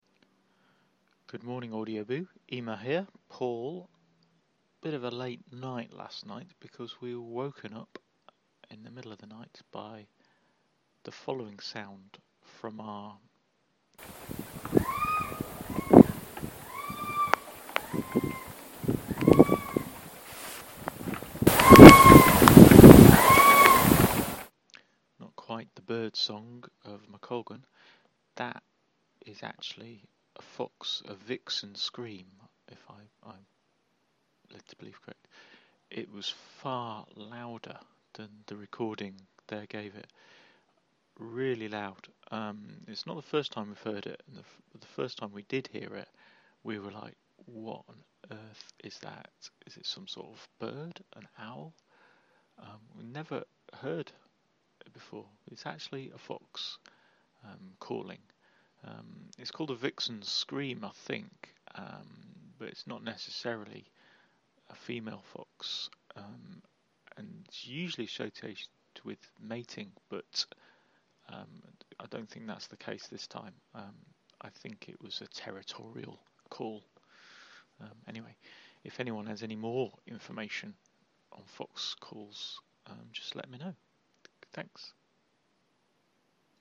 Vixen scream